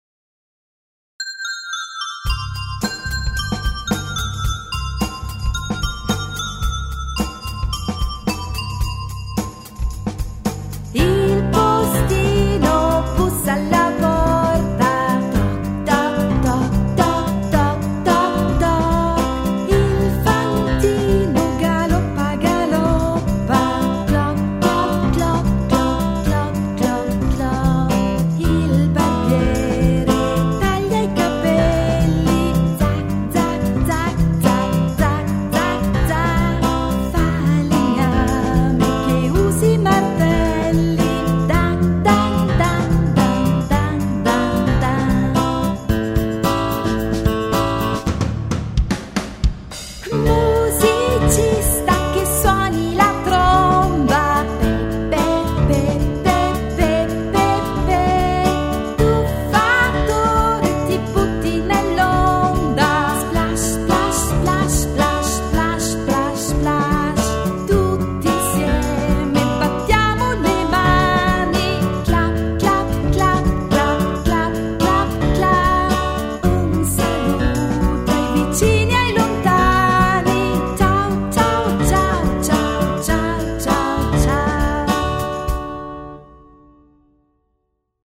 canzone